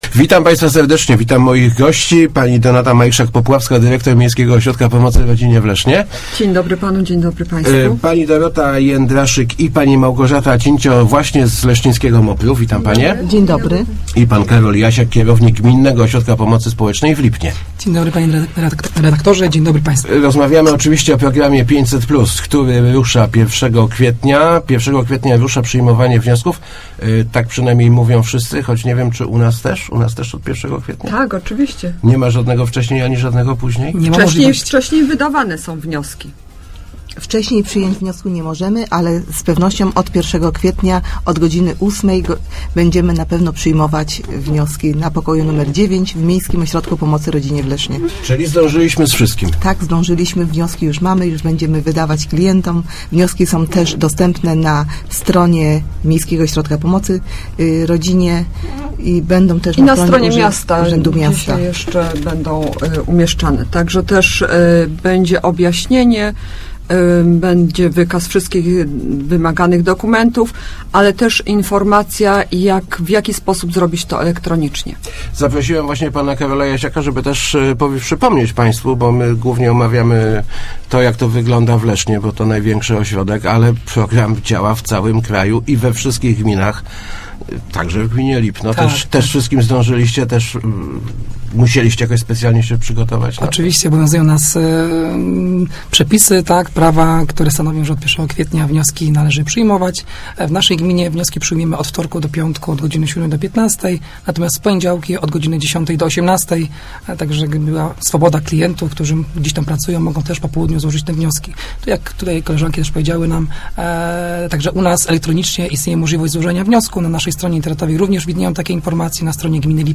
Na te i inne pytania przez dwie godziny odpowiadali eksperci od pomocy spo�ecznej z Miejskiego O�rodka Pomocy Rodzinie i Gminnego O�rodka Pomocy Spo�ecznej w Lipnie, którzy byli go��mi Rozmów Elki.